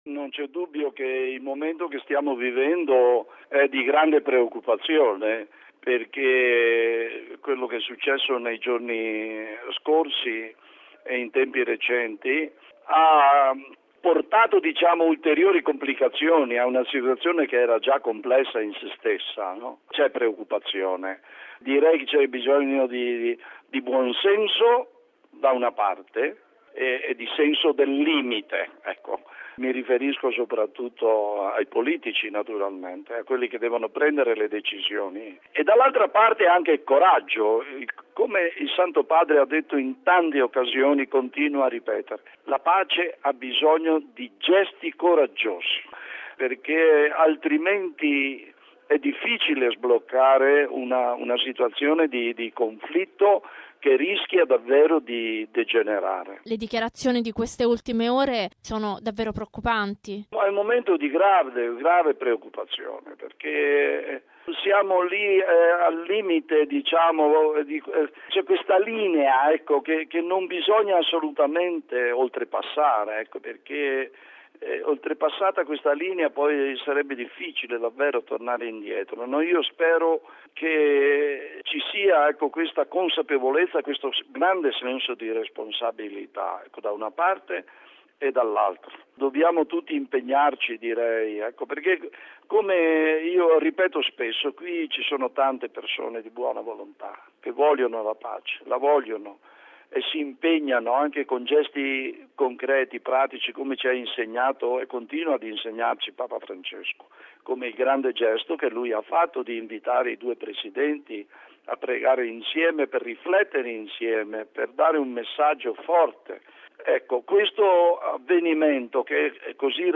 Grande la preoccupazione anche di mons. Giuseppe Lazzarotto, nunzio apostolico in Israele e delegato apostolico a Gerusalemme e Palestina.